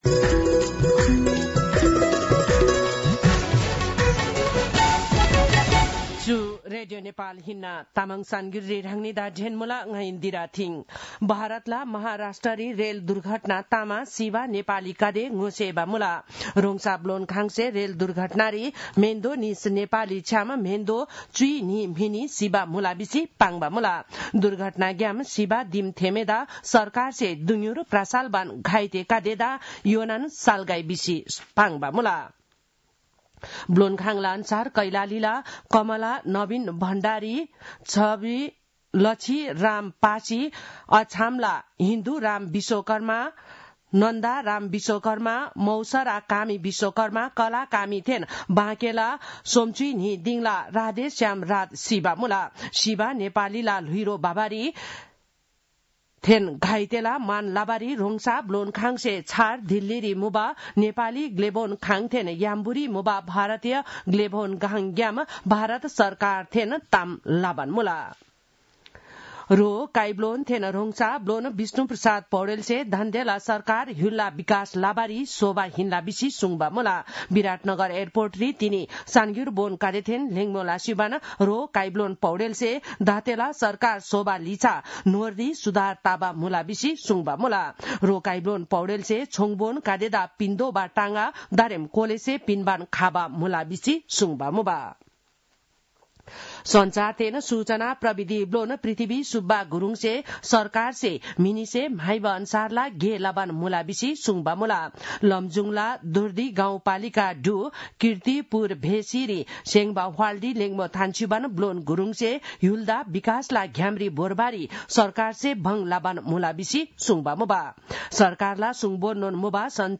तामाङ भाषाको समाचार : १३ माघ , २०८१